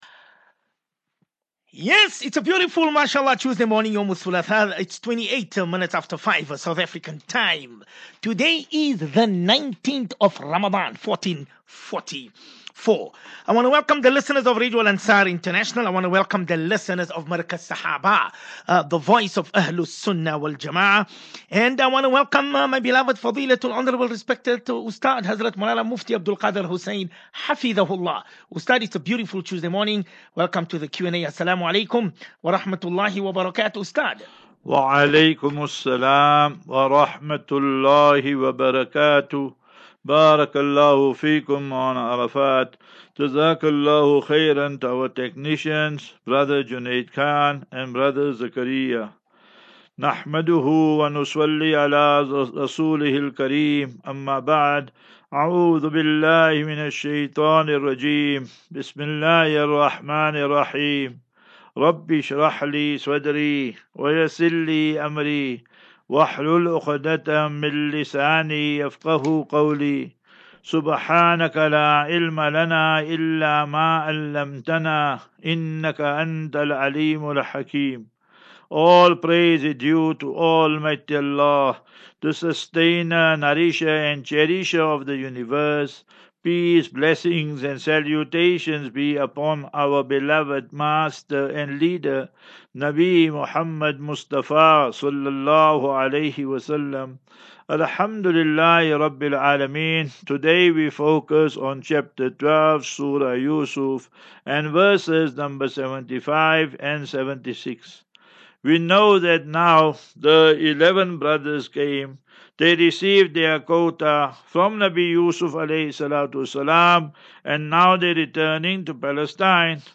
As Safinatu Ilal Jannah Naseeha and Q and A 11 Apr 11 Apr 23 Assafinatu